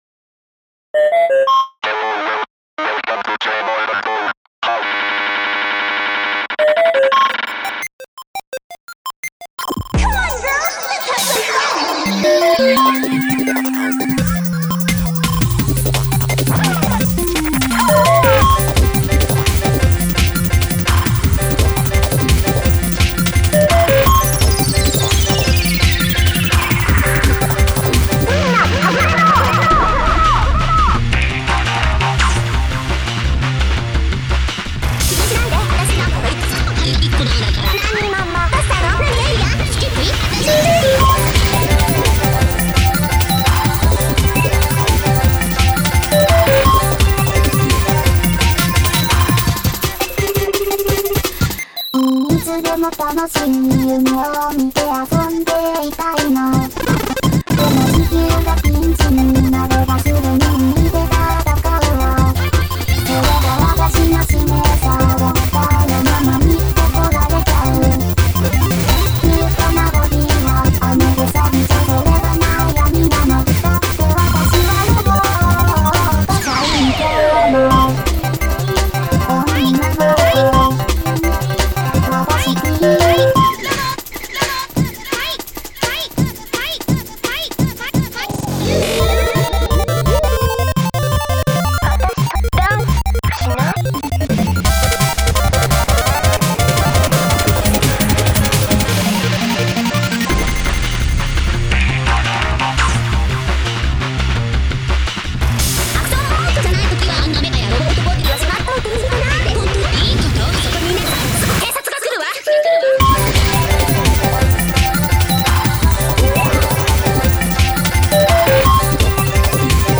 ジャングルでチップチューンでテクノでボーカロイドなので
ガチャガチャした曲になっておりますので
結局ギター入ってるしなぁ
(Jngl-Chiptuned mix rev.B)
VOCALOID2 巡音ルカ(Eng)
Auto-Tune 7